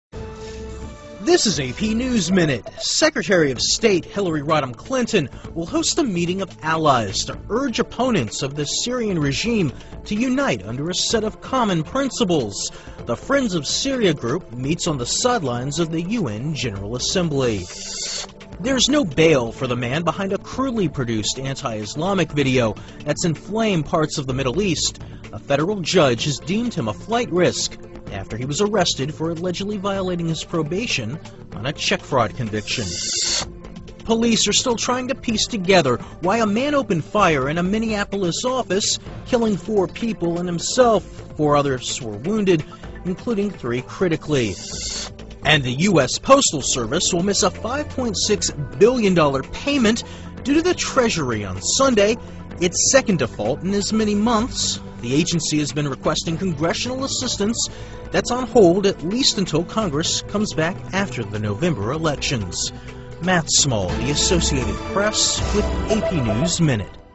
在线英语听力室美联社新闻一分钟 AP 2012-10-07的听力文件下载,美联社新闻一分钟2012,英语听力,英语新闻,英语MP3 由美联社编辑的一分钟国际电视新闻，报道每天发生的重大国际事件。电视新闻片长一分钟，一般包括五个小段，简明扼要，语言规范，便于大家快速了解世界大事。